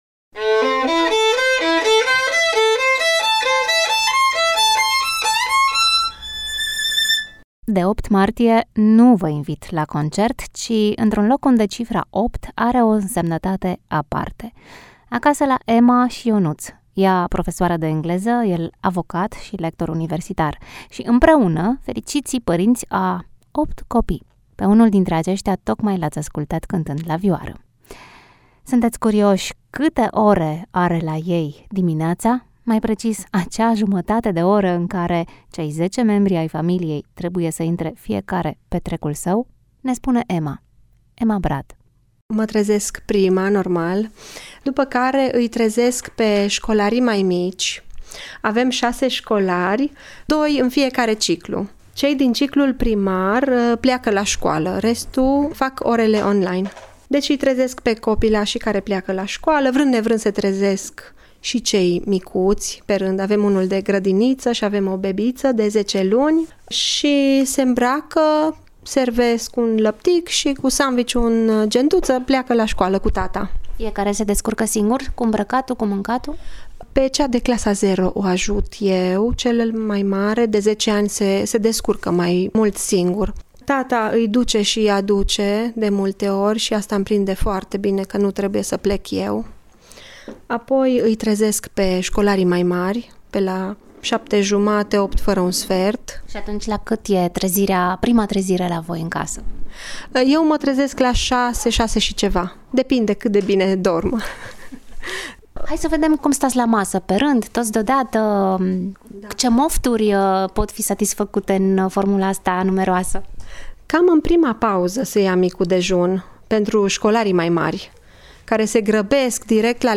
Dar ajungem și la ce ne interesează mai mult: cum se soluționează conflictele într-o familie atât de numeroasă. Care este relația cu televizorul și gadget-urile și, bineînțeles, de ce opt copii. Toate acestea în interviul realizat